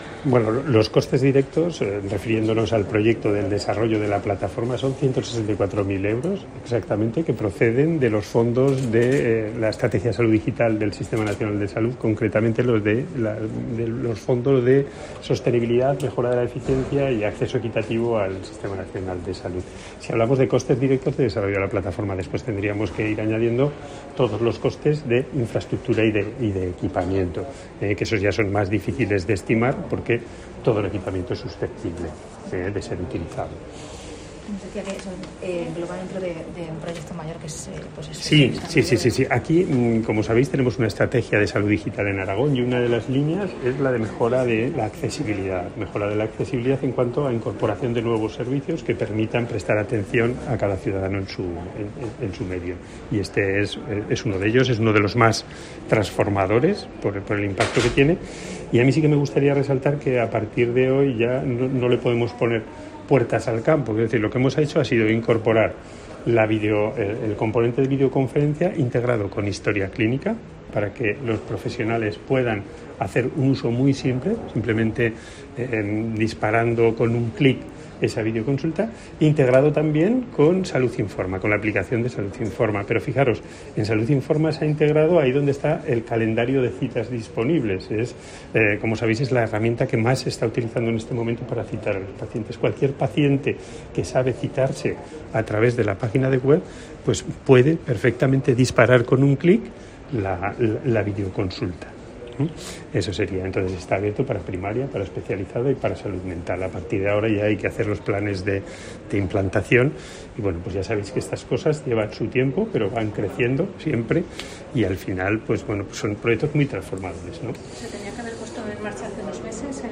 Juan Coll, director general de Transformación Digital, explica los detalles de las videconsultas sanitarias.